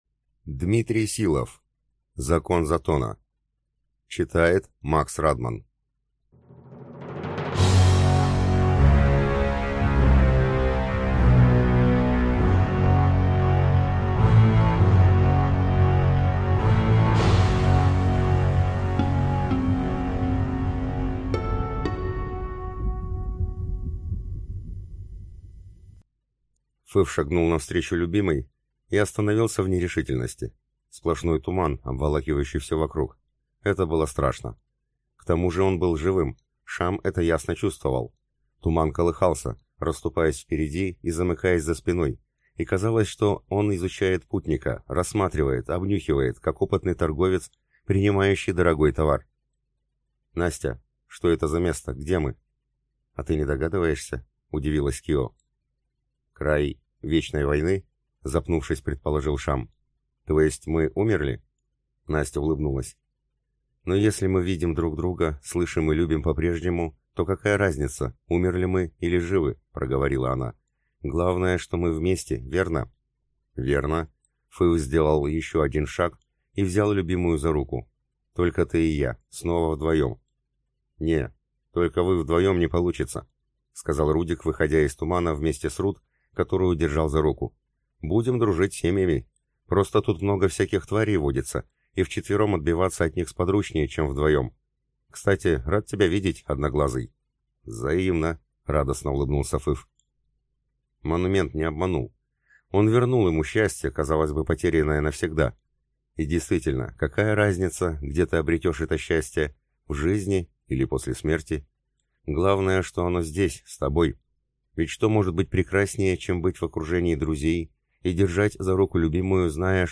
Аудиокнига Закон затона | Библиотека аудиокниг
Прослушать и бесплатно скачать фрагмент аудиокниги